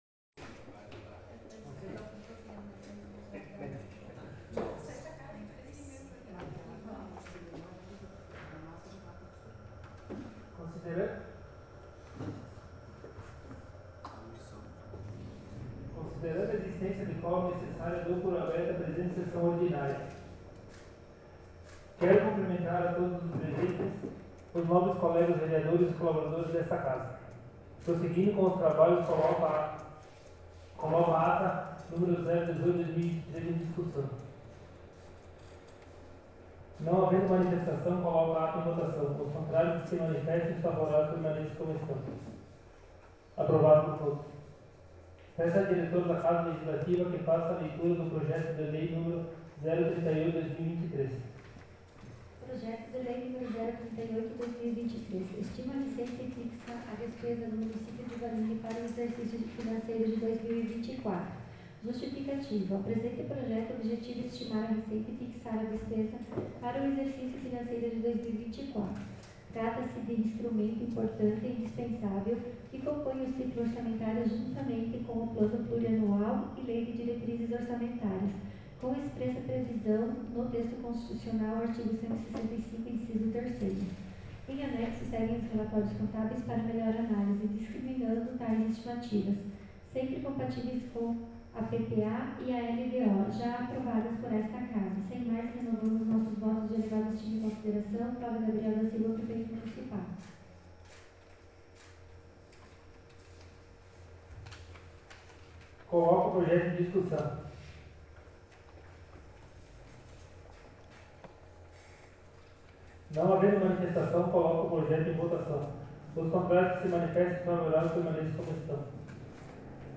Em anexo arquivo de gravação em áudio da Sessão Ordinária realizada na Câmara de Vereadores de Vanini na data de 07/11/2023.
Gravação em áudio da Sessão Ordinária do dia 07 de Novembro de 2023